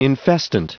Prononciation du mot infestant en anglais (fichier audio)